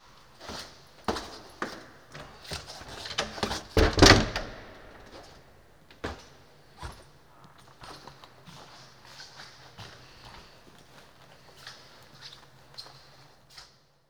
Index of /90_sSampleCDs/Propeller Island - Cathedral Organ/Partition N/DOORS+STEPS
CH.-DOOR 1-R.wav